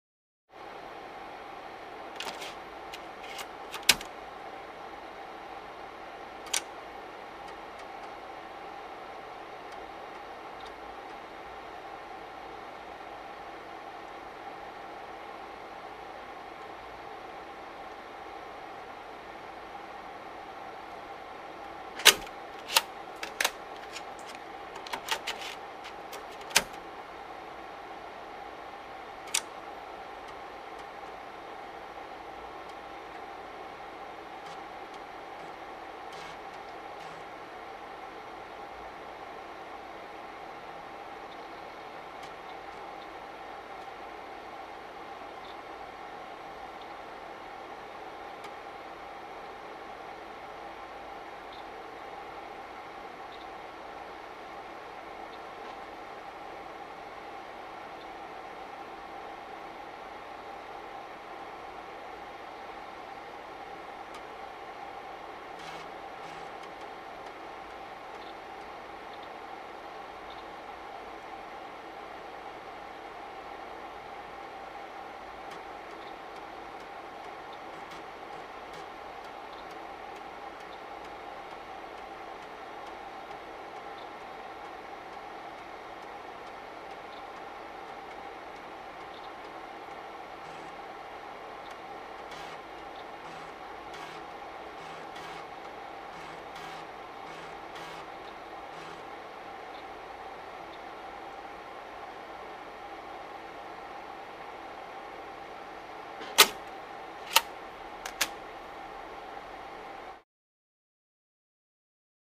PC Floppy Drive | Sneak On The Lot
PC Floppy Disk Drive; Read / Write; Desktop Computer Floppy Disk Drive; Computer Fan Idle / Floppy Insert / Read / Write / Eject, Close Perspective.